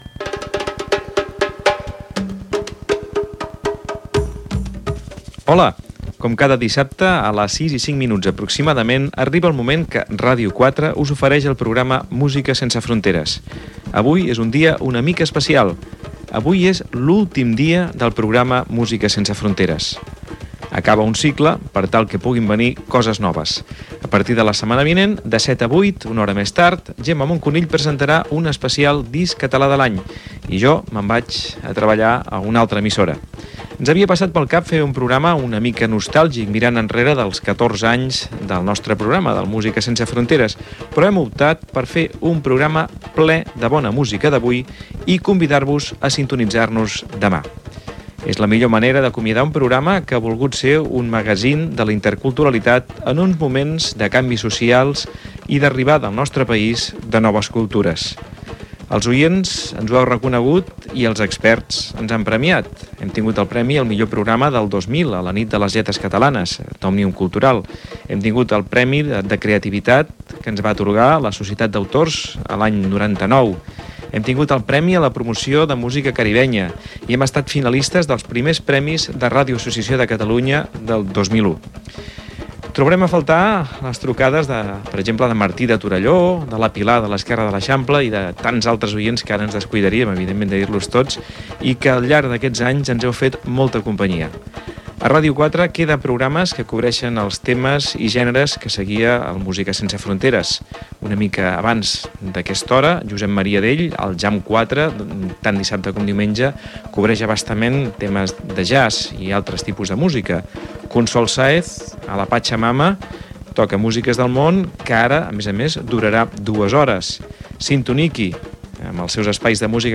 Salutació inicial de l'últim programa, sumari, 21 Premi Disc Català de l'Any.
Musical